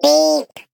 Sfx_tool_spypenguin_vo_horn_05.ogg